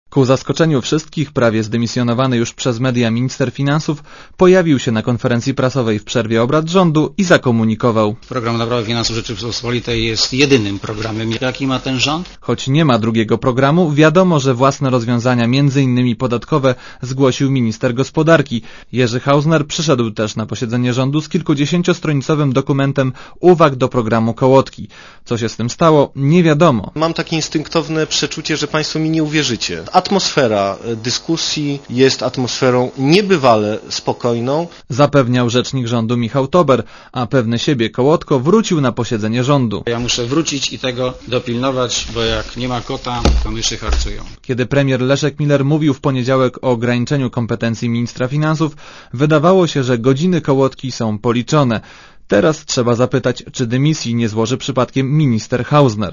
Relacja reportera Radia Zet (220Kb)